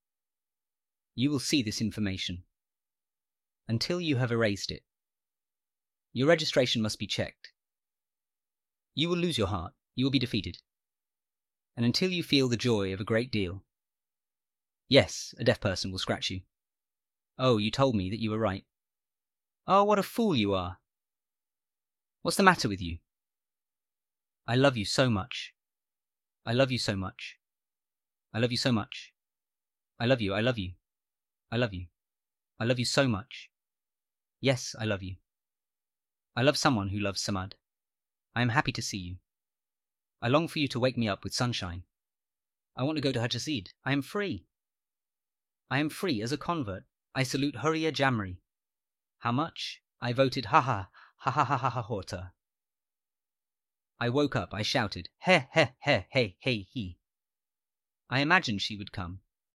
Teossarjaan kuuluvassa ääniteoksessa Interpretations synteettinen ääni lukee tulkintoja.